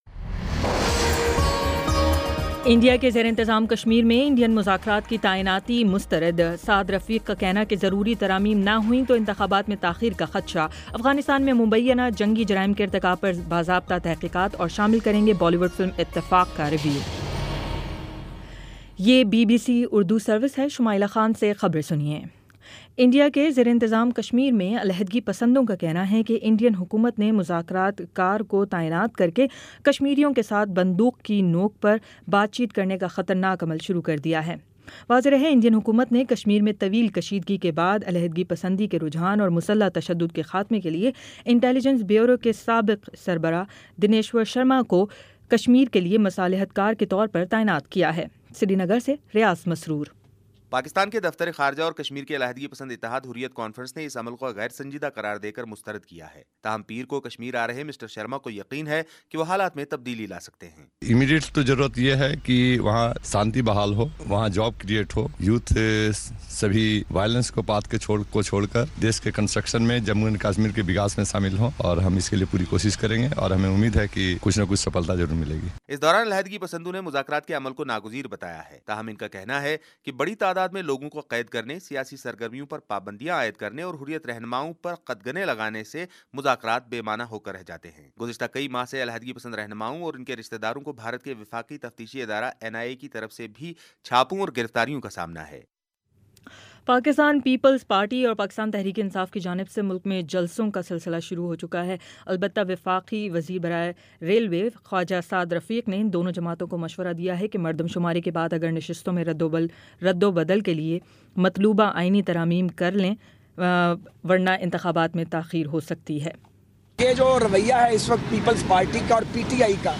نومبر 04 : شام پانچ بجے کا نیوز بُلیٹن